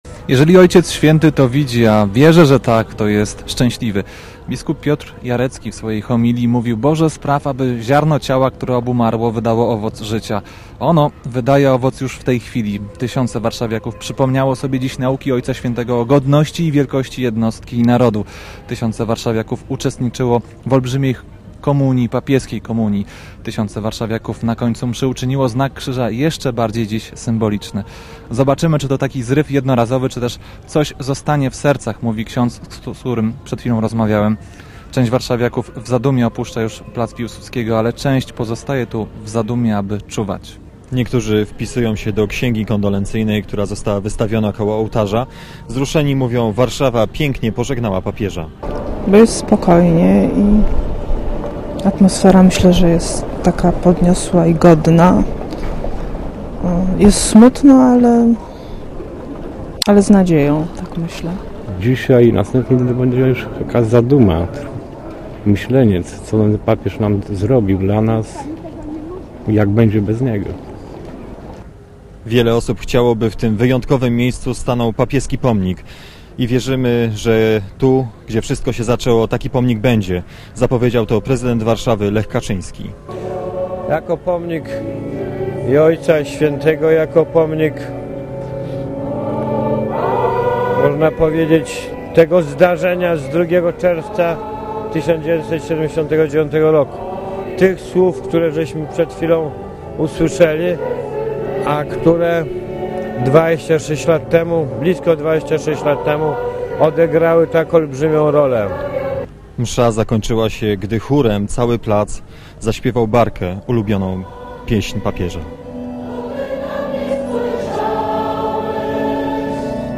Blisko 150 tys. osób uczestniczyło w niedzielę we mszy w intencji Jana Pawła II odprawionej na Placu Piłsudskiego w Warszawie, gdzie zmarły papież odprawił mszę podczas swej pierwszej pielgrzymki do Polski
warszawa_-_koniec_mszy.mp3